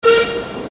Steam